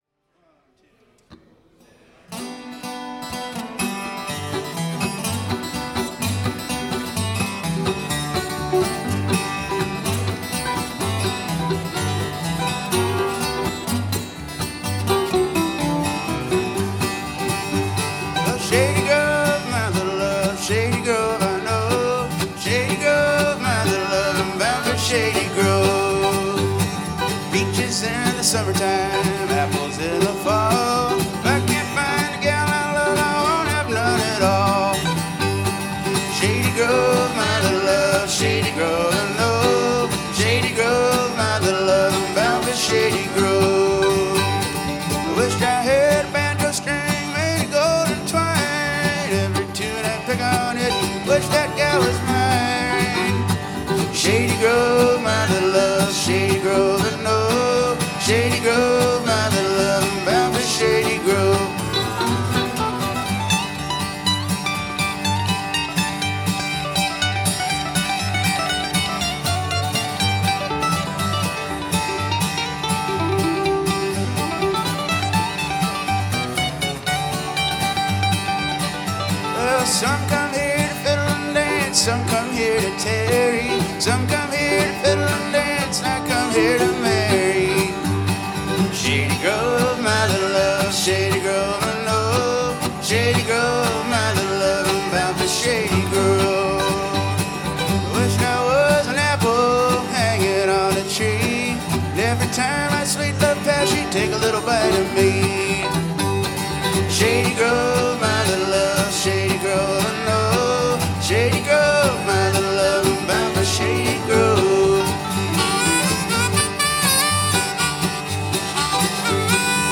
Wallenpaupack Brewing Company Hawley, PA
Mandolin/Electric Guitar/Vocals
Bass/Vocals